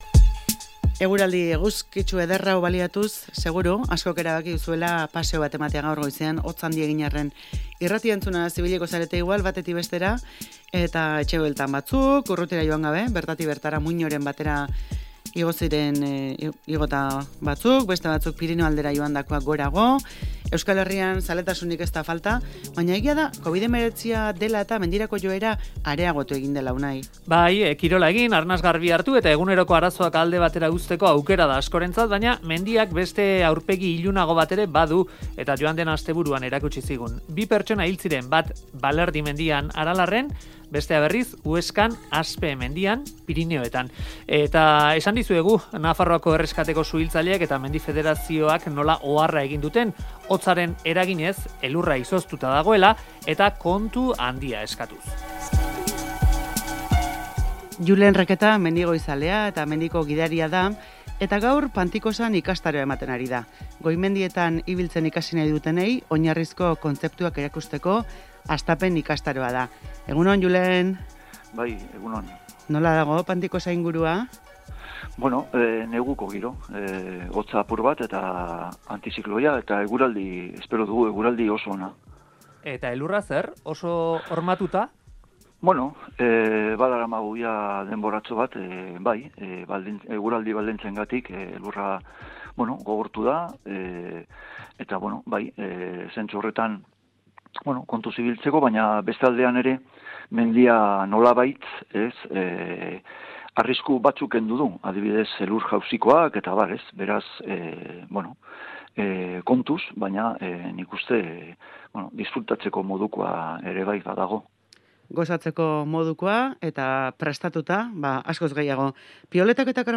Audioa: Segurtasuna izan dugu hizpide mendizale eta adituekin, azken aldian izan diren ezbeharren ondotik. Formakuntzaren garrantzia azpimarratu digute.